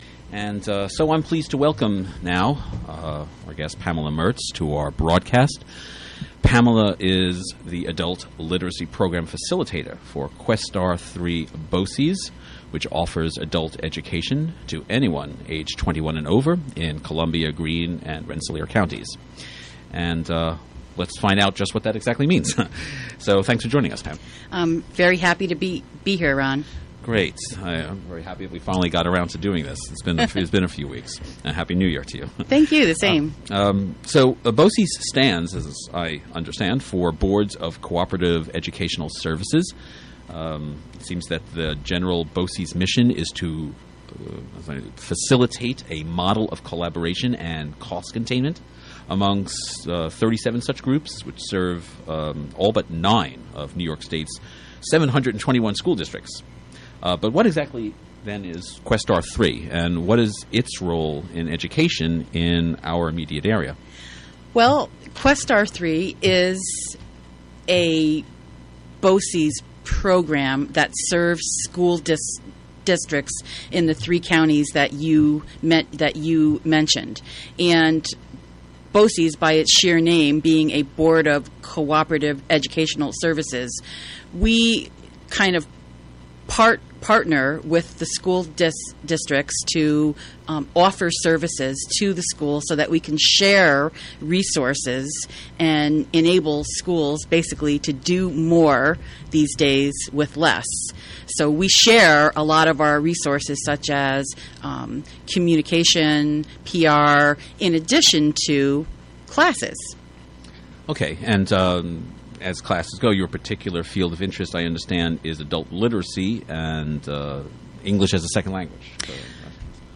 Interview on "WGXC Afternoon Show" at Catskill Community Center.